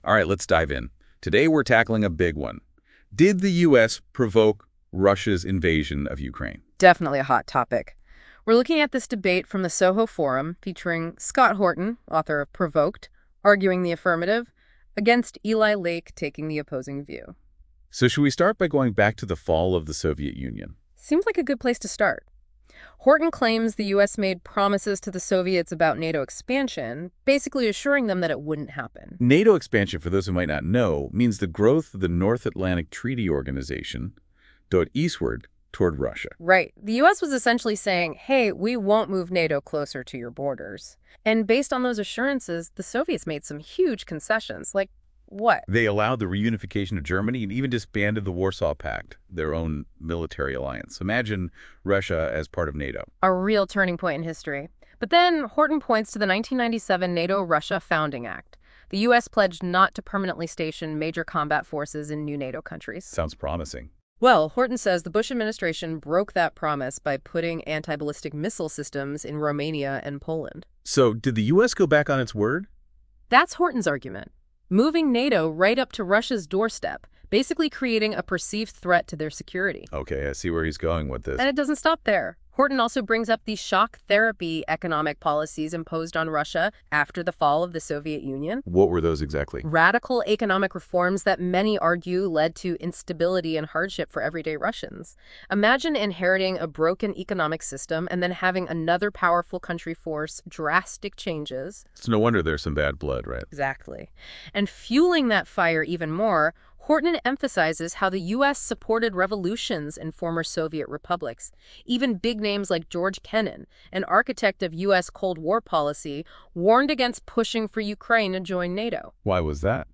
Russia, Ukraine, and the New Cold War: A Debate #242